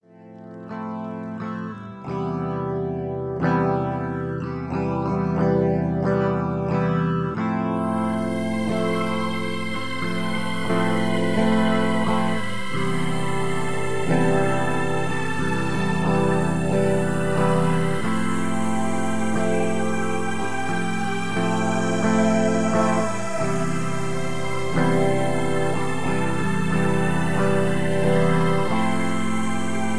Atmospheric mood piece with haunting melodic synth lines.